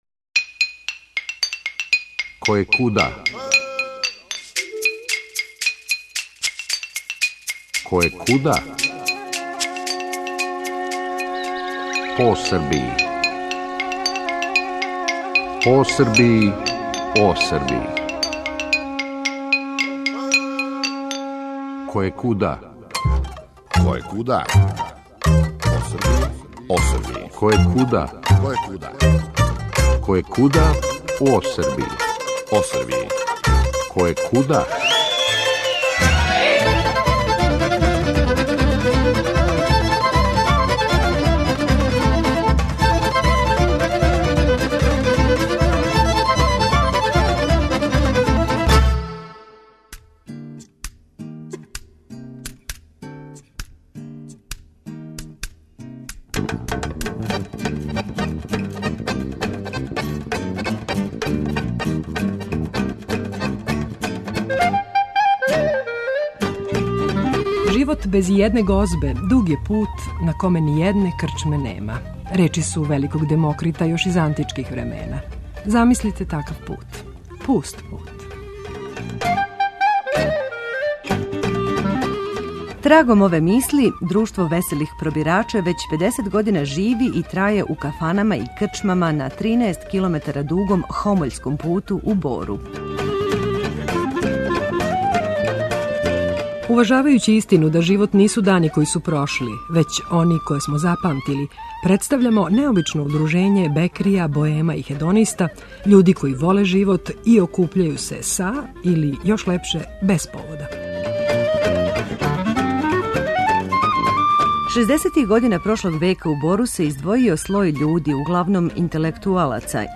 О овом несвакидашњем удружењу, које се окупља у борским кафанама густирајући храну пиће и музику (одатле и назив) разговарамо са активним пробирачима.